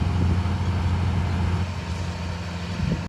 理工一館 D 棟拆除作業，由作者拍攝，攝於2025年9月4日。